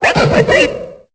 Cri de Baggaïd dans Pokémon Épée et Bouclier.